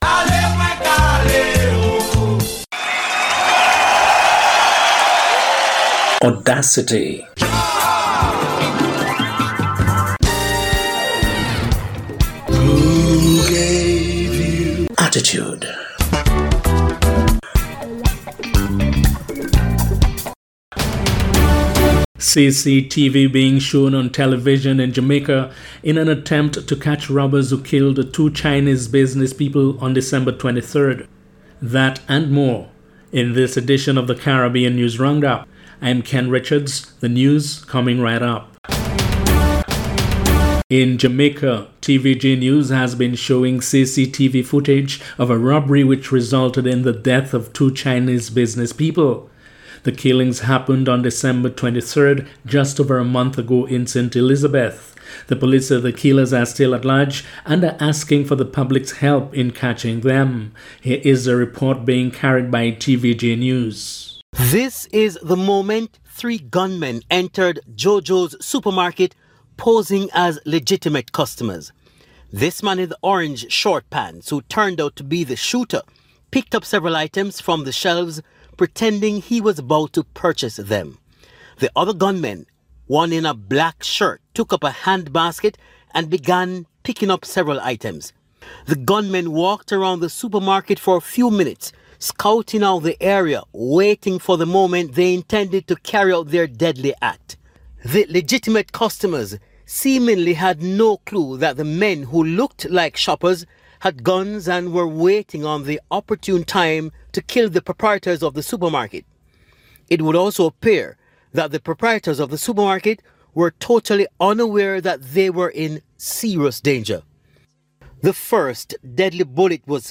January 31 Monday Caribbean News Roundup￼
January-31-Monday-Caribbean-News-Roundup.mp3